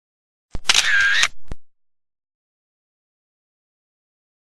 Lý tưởng cho lồng tiếng video, tạo hiệu ứng hấp dẫn cho video. sound effects nervous transition sound